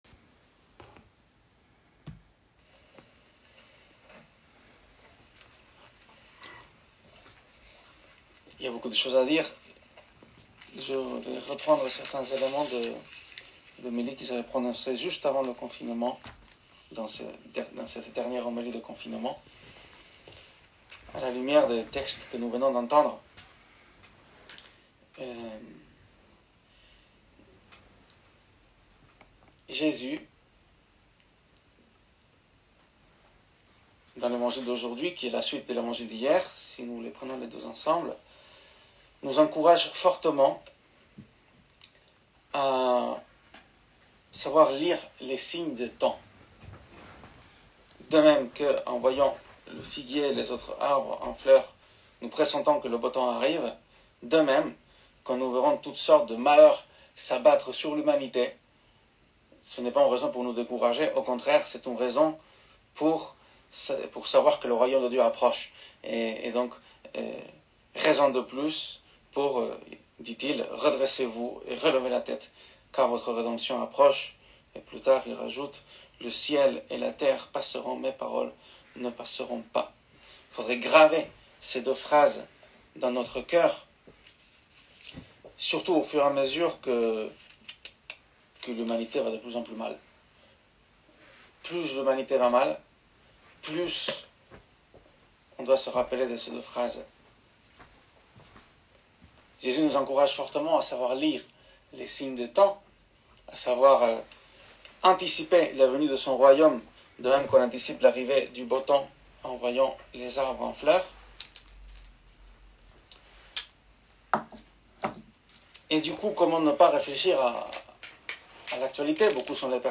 LIEN AUDIO HOMELIE DU 27 NOV 2020: